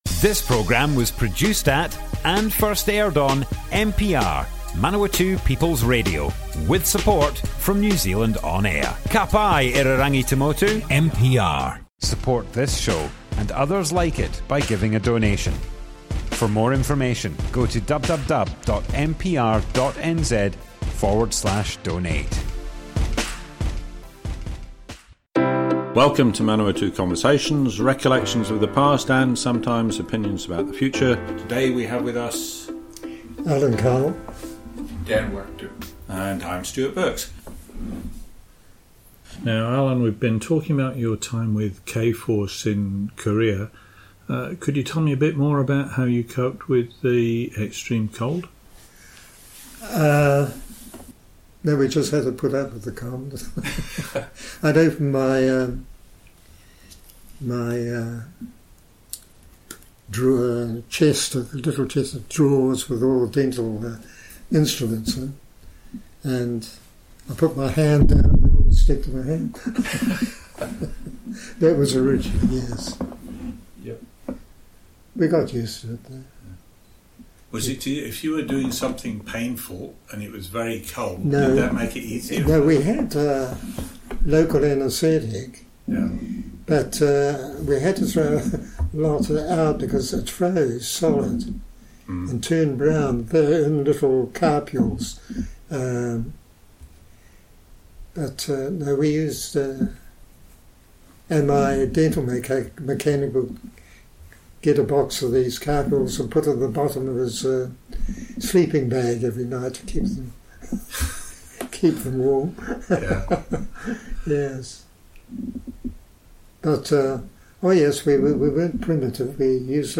Manawatu Conversations Object type Audio More Info → Description Broadcast on Manawatu People's Radio, 9th February 2021.
oral history